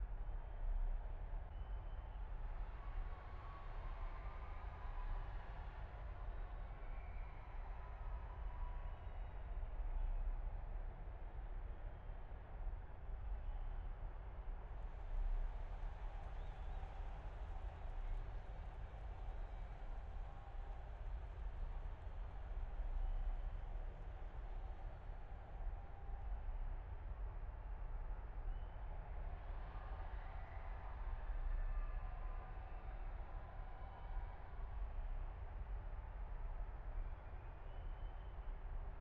sfx-ambience-loop-tft-set10.ogg